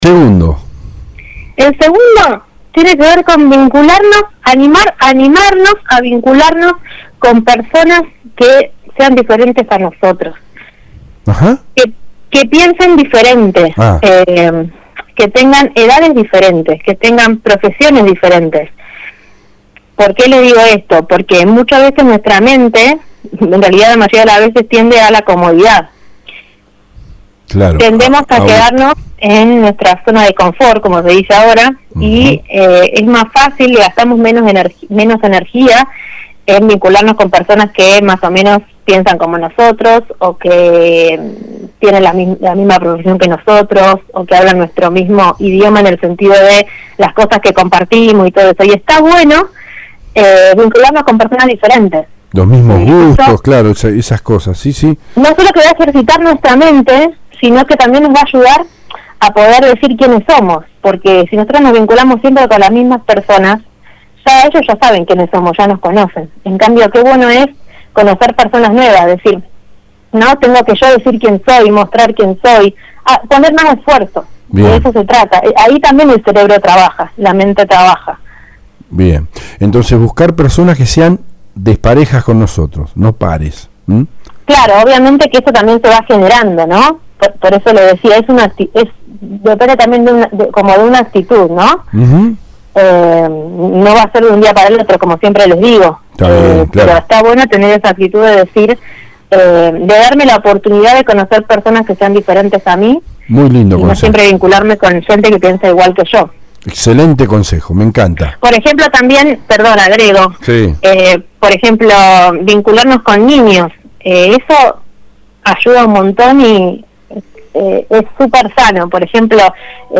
Acompañamos con los audios en vivo.